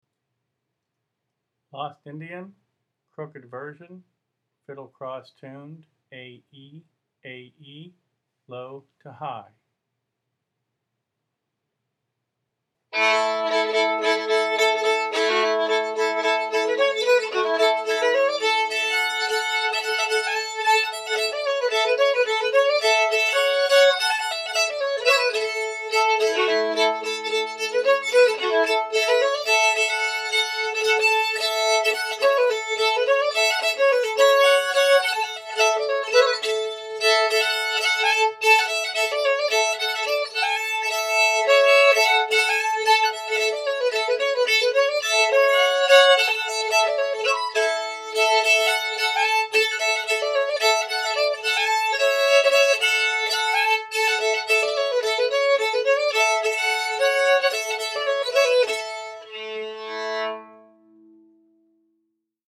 Set 4: Songs 61-117 (Advanced Arrangements)